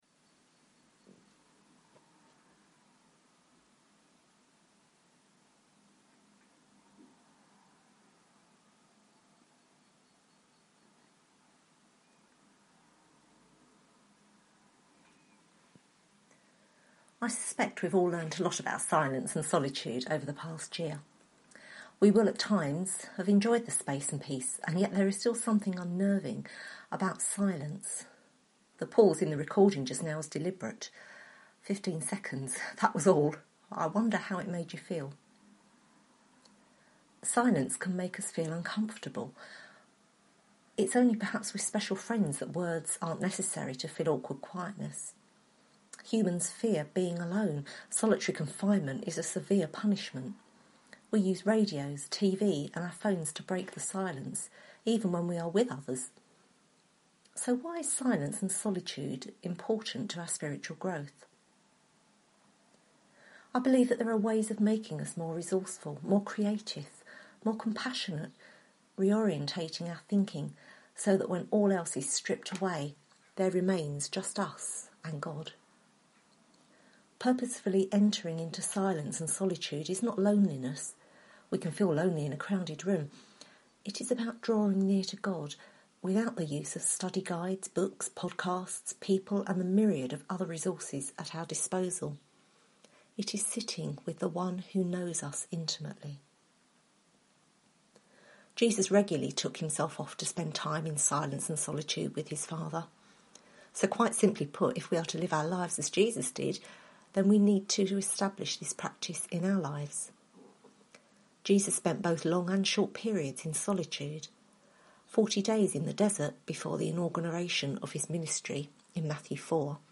Note, the start of this podcast is intentionally silent :)
Series: Cultivating Spiritual Practices Service Type: Sunday Morning